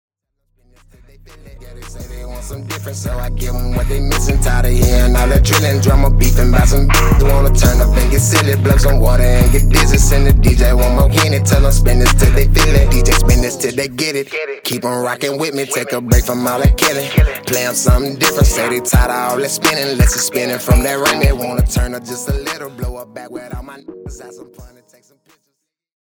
” a club anthem ready to ignite any party.